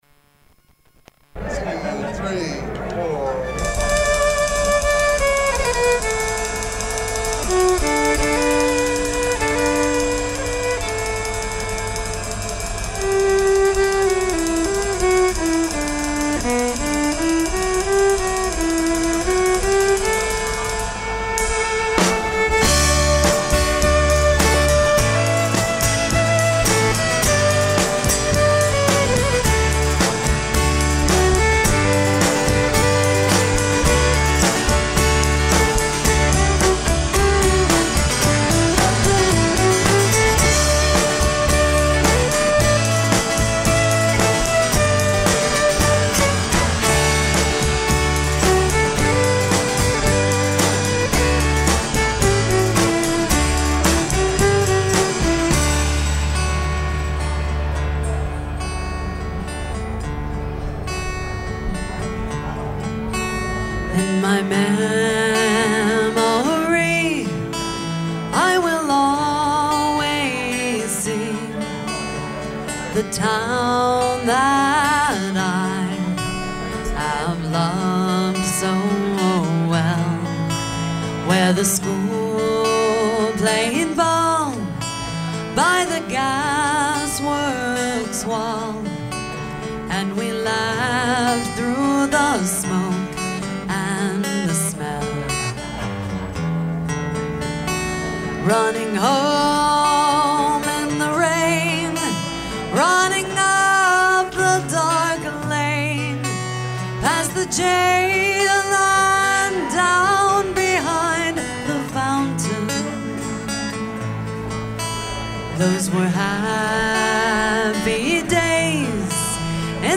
Live Tracks